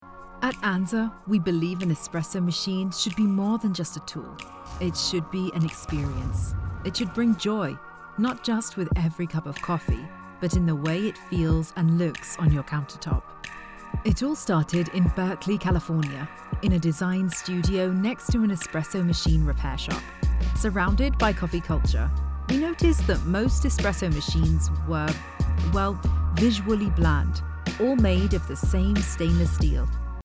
Inglés (Británico)
Profundo, Natural, Llamativo, Cálida, Suave
Explicador